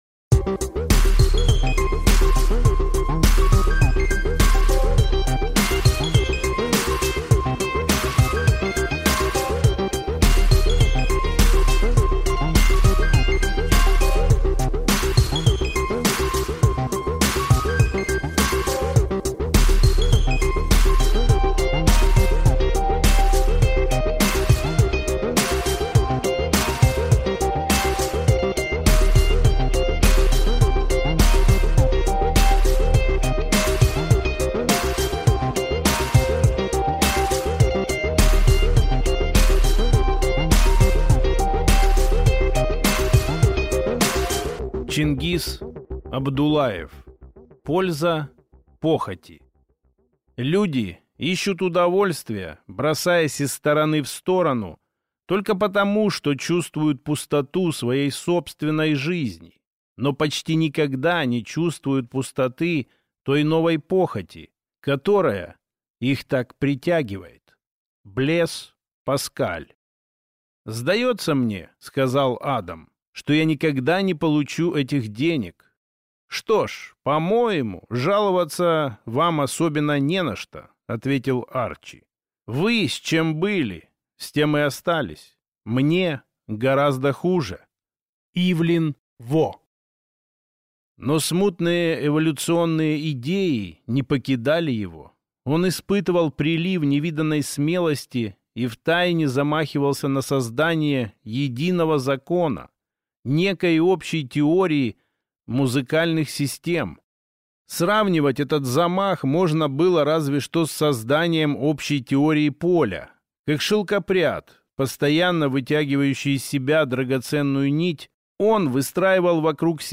Аудиокнига Польза похоти | Библиотека аудиокниг
Читает аудиокнигу